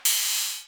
One of my A4 hihats, created that way (random pick :wink: ):
This is a nice hihat - super metalic!